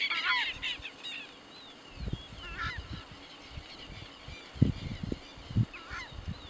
Southern Screamer (Chauna torquata)
Country: Argentina
Location or protected area: Santa María
Condition: Wild
Certainty: Recorded vocal